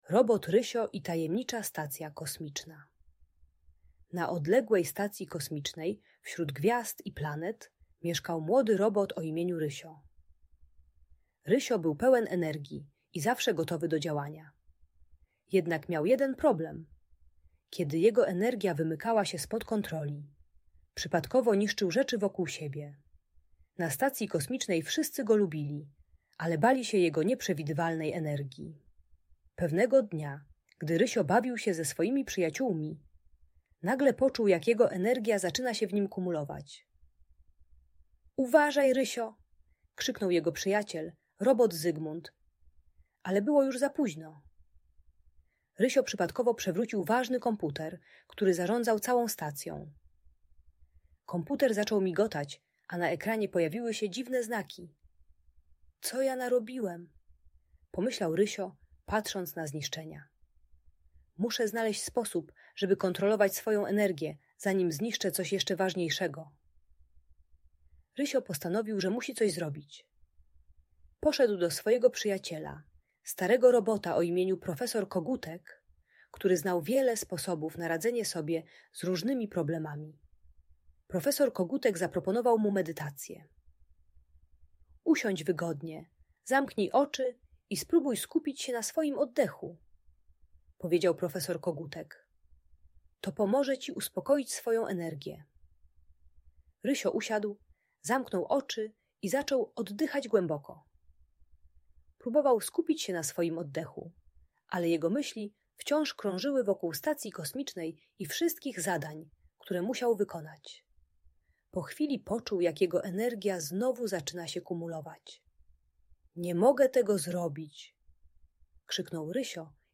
Historia robota Rysia i tajemniczej stacji kosmicznej - Audiobajka dla dzieci